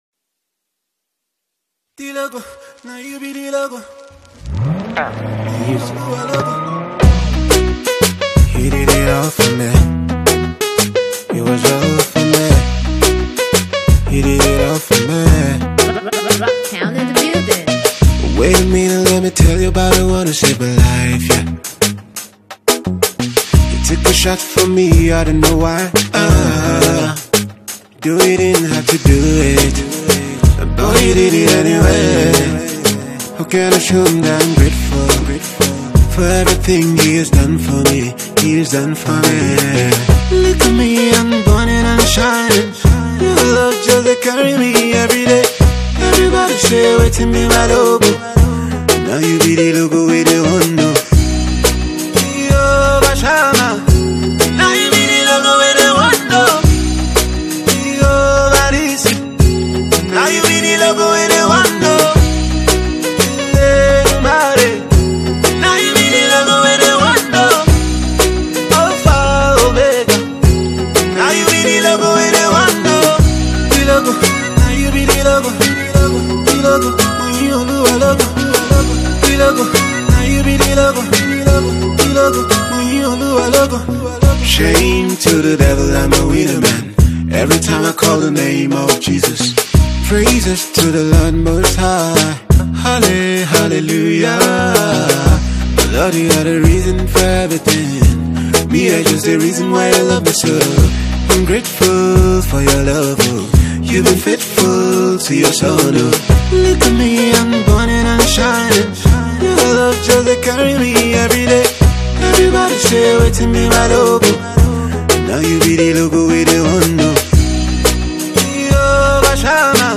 Gospel music singer